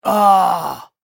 دانلود آهنگ دعوا 38 از افکت صوتی انسان و موجودات زنده
دانلود صدای دعوا 38 از ساعد نیوز با لینک مستقیم و کیفیت بالا
جلوه های صوتی